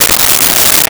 Metal Zipper 01
Metal Zipper 01.wav